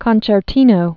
(kŏnchĕr-tēnō)